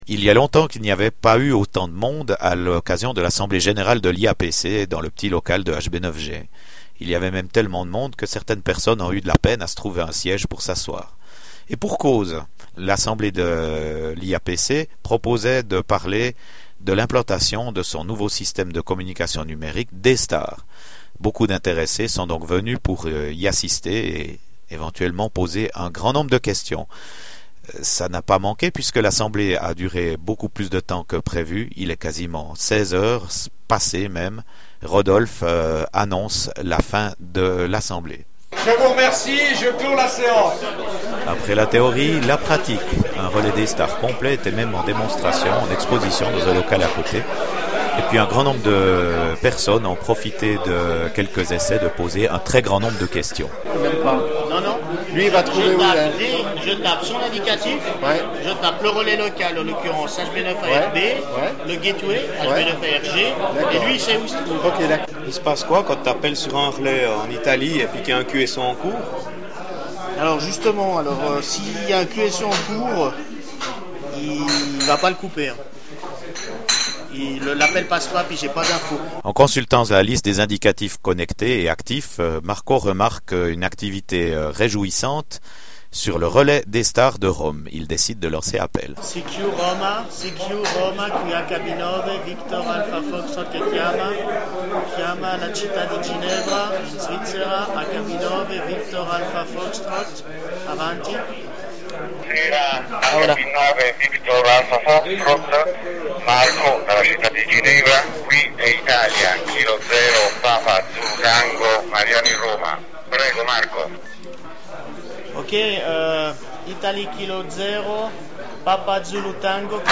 L’AG de l’IAPC samedi 5 dernier, a été très prometteuse, la petite salle du local de HB9G était quasi comble.
Pour ceux qui n’ont pas pu participer ou ont du partir avant, voici un petit reportage son que j’ai fait.